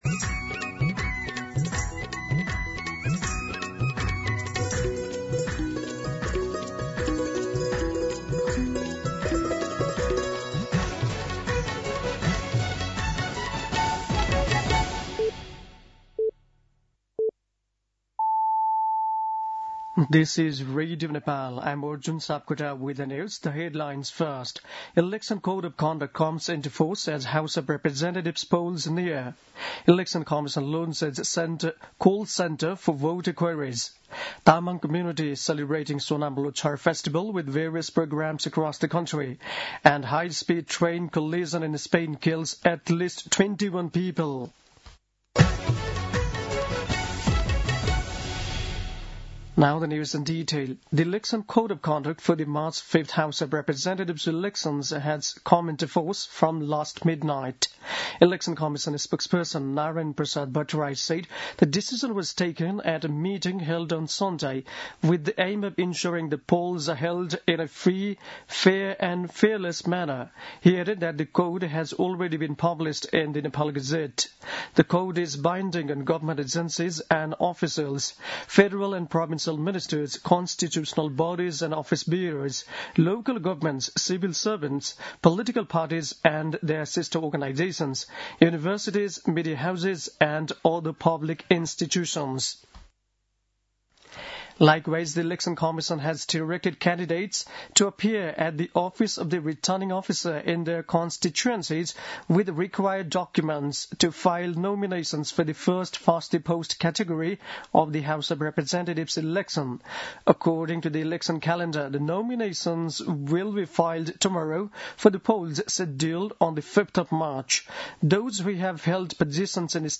दिउँसो २ बजेको अङ्ग्रेजी समाचार : ५ माघ , २०८२
2-pm-News-.mp3